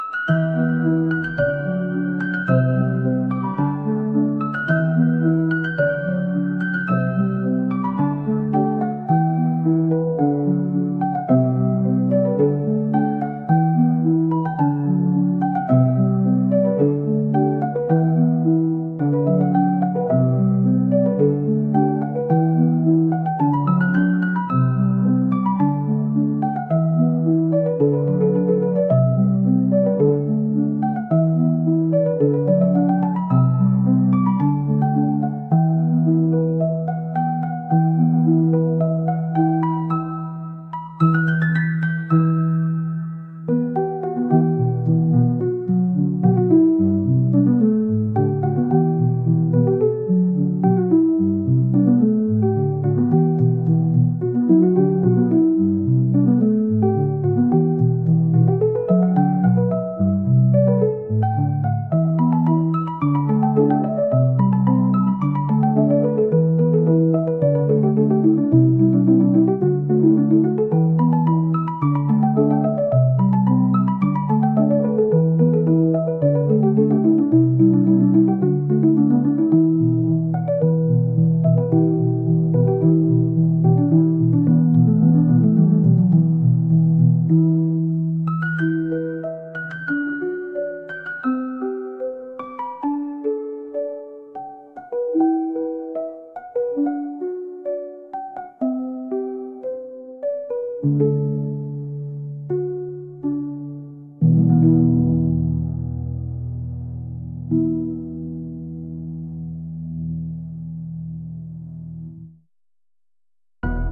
癒し、リラックス